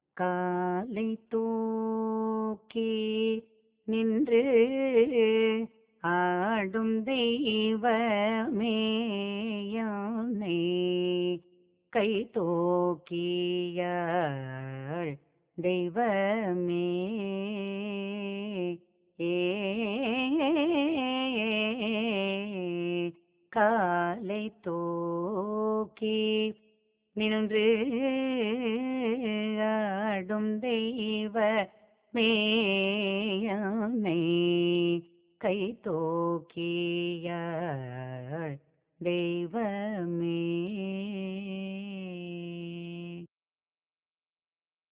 இராகம் : யதுகுலகாம்போதி தாளம் : ஆதி